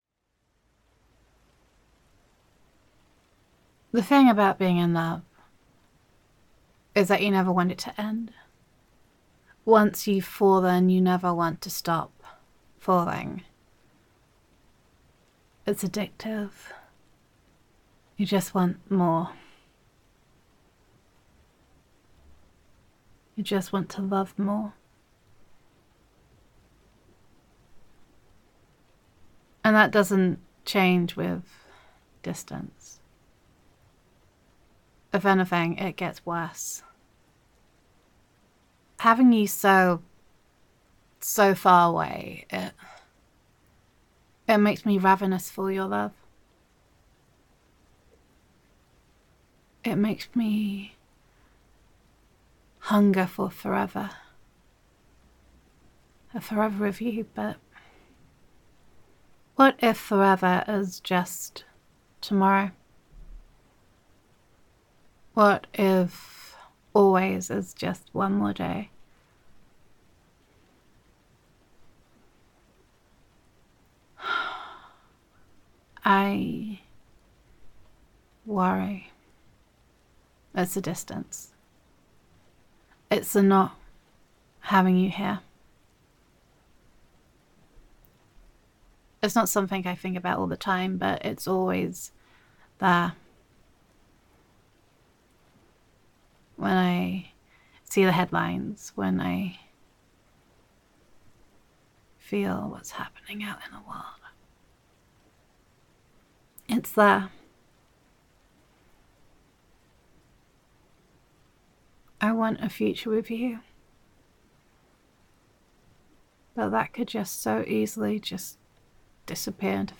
[F4A] Ravenous to Love You [Long Distance Love][Missing You][Girlfriend Roleplay][Soft Rain][Gender Neutral][When the One You Love Is Far Away Distance Multiplies Doubt]